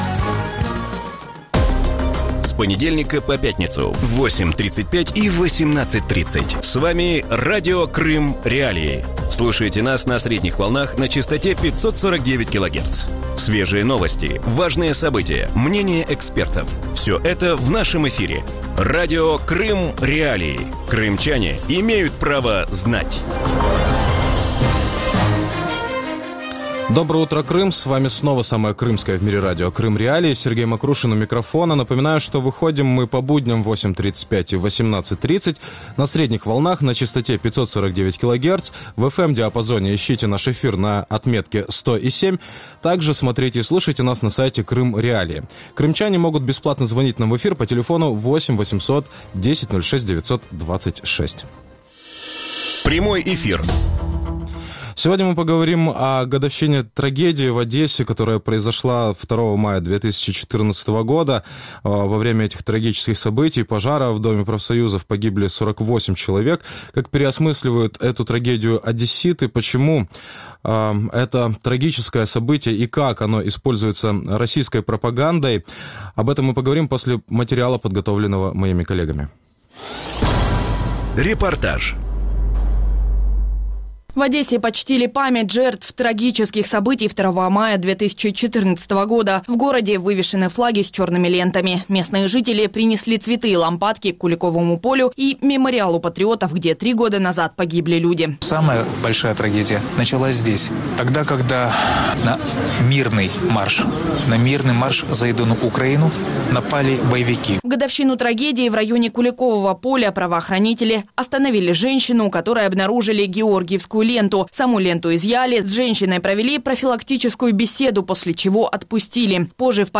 У ранковому ефірі Радіо Крим.Реалії говорять про річницю трагедії в Одесі 2 травня 2014 року. Як минає розслідування справи про загибель 42 людей під час пожежі в Будинку профспілок?